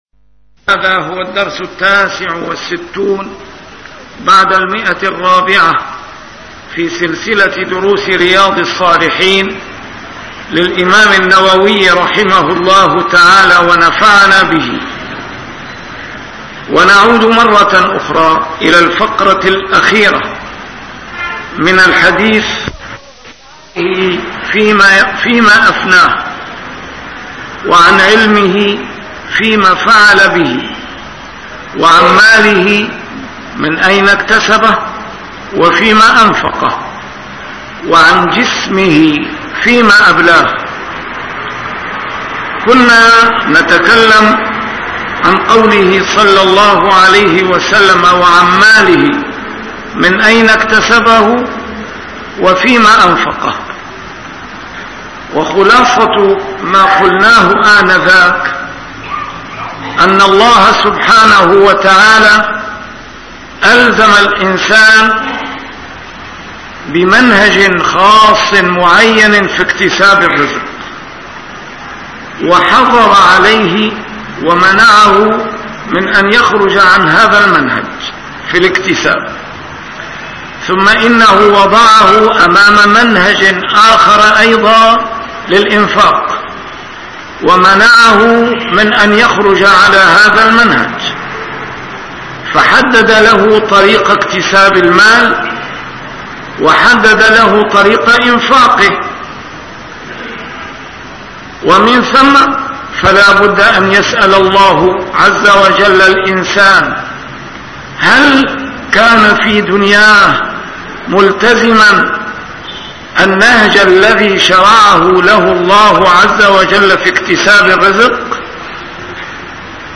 نسيم الشام › A MARTYR SCHOLAR: IMAM MUHAMMAD SAEED RAMADAN AL-BOUTI - الدروس العلمية - شرح كتاب رياض الصالحين - 469- شرح رياض الصالحين: الخوف